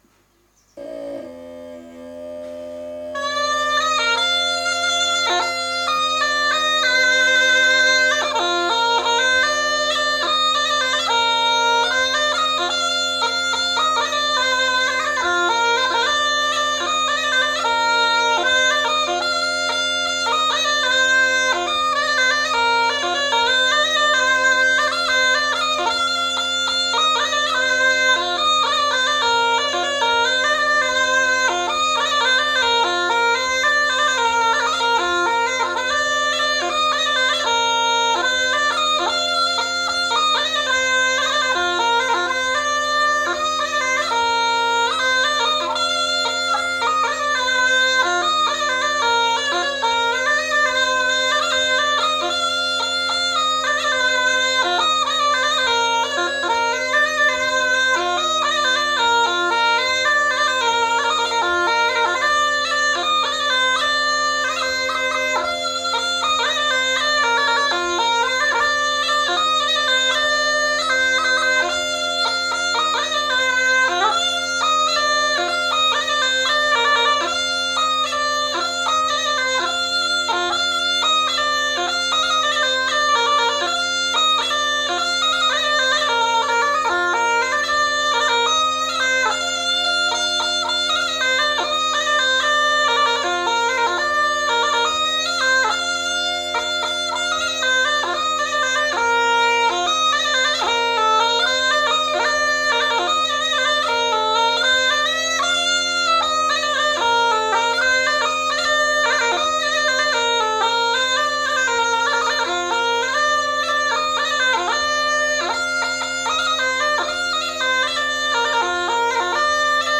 Aire culturelle : Cabardès
Lieu : Mas-Cabardès
Genre : morceau instrumental
Instrument de musique : craba